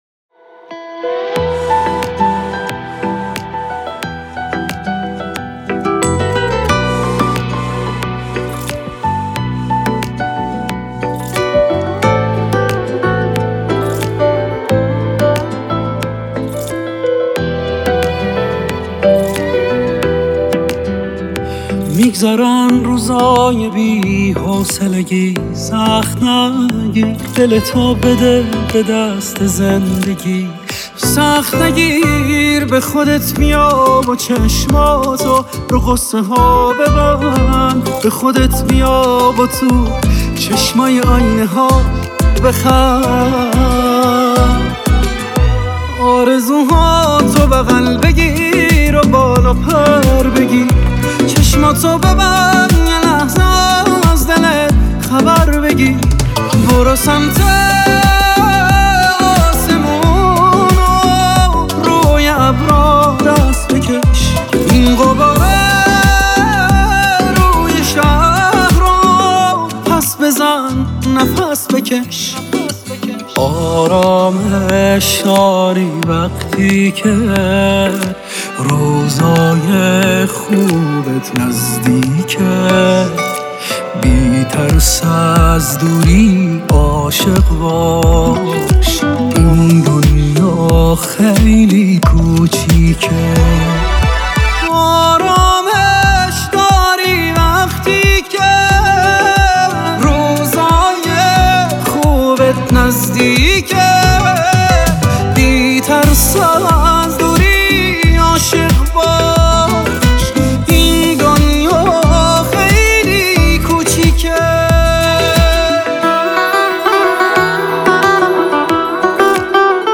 خواننده سبک پاپ است